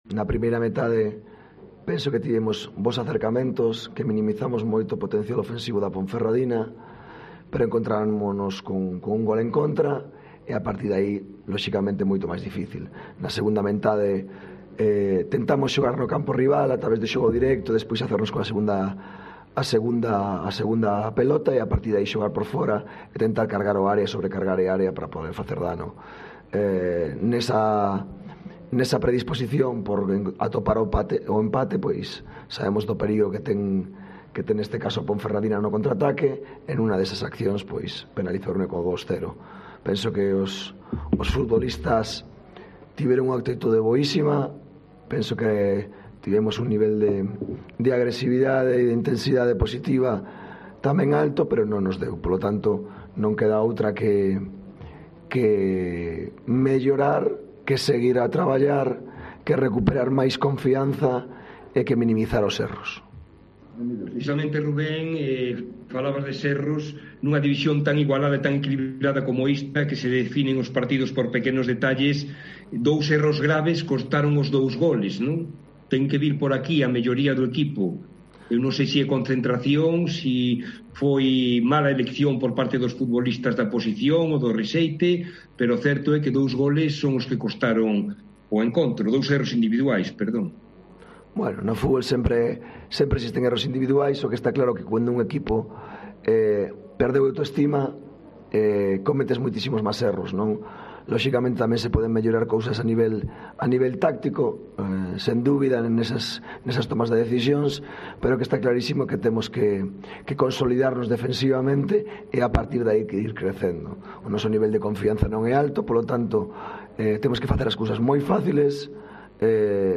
Tras cada partido de la Deportiva Ponferradina la web de COPE Bierzo te ofrece todo lo que se ha dicho en sala de prensa con las voces de los protagonistas.